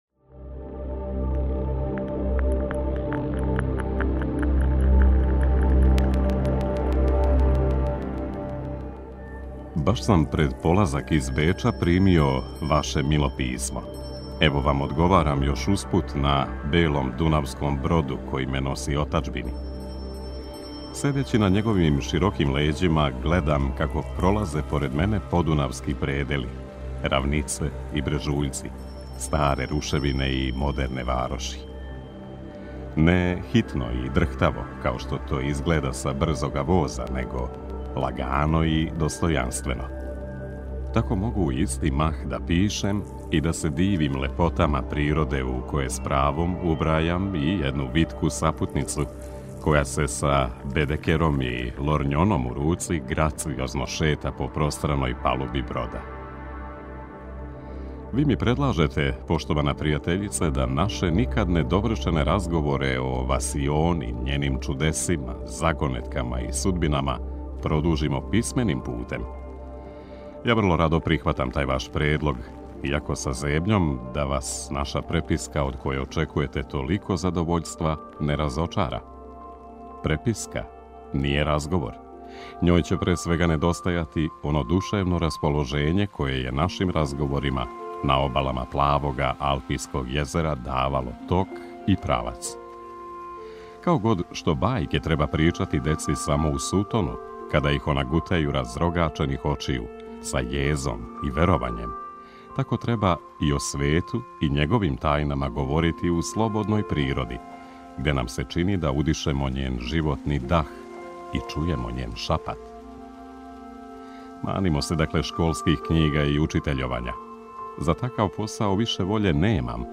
Формат: аудио прича.